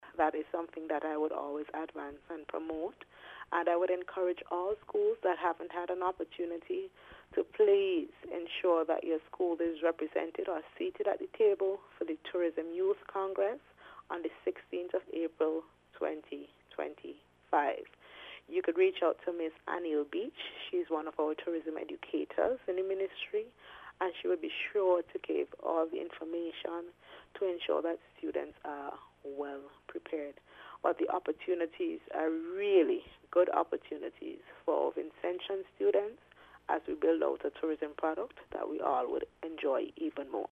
Several schools have already registered to participate in this year’s edition of the Tourism Youth Congress Permanent Secretary in the Ministry of Tourism Dr. Tamira Browne speaking to NBC News said this initiative is an exciting opportunity.